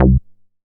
MoogDumb 003.WAV